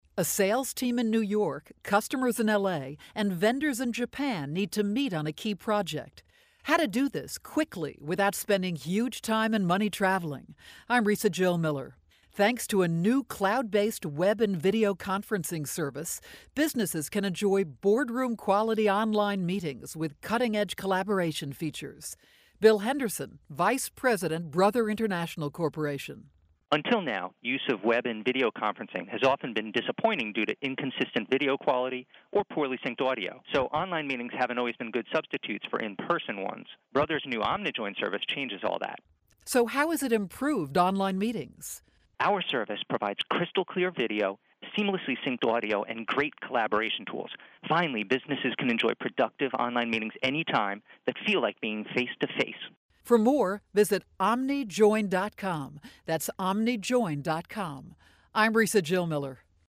June 11, 2012Posted in: Audio News Release